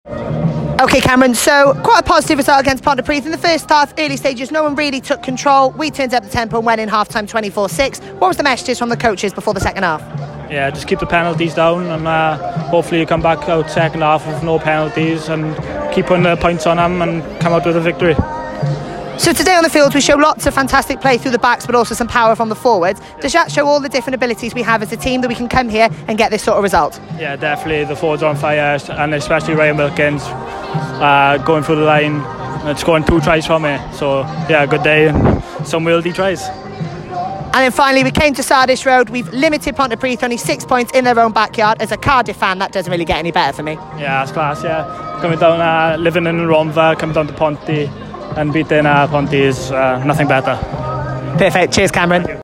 Post-Match Interviews